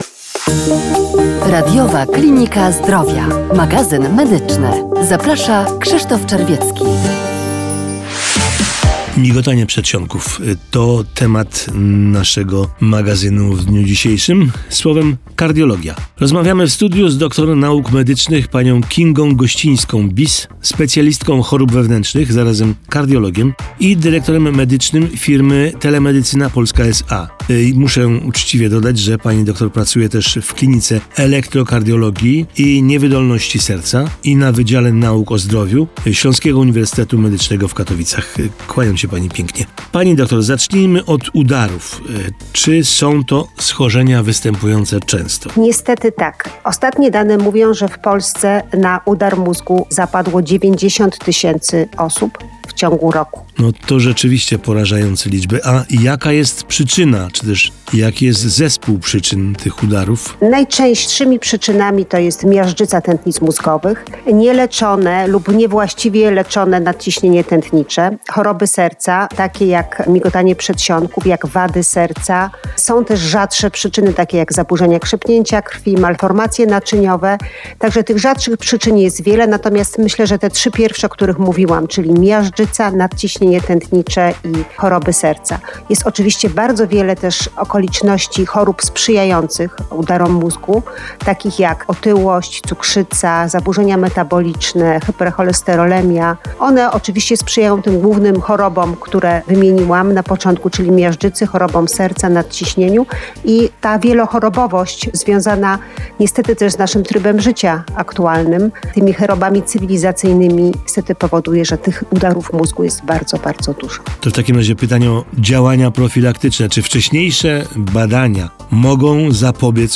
Radio PłockFM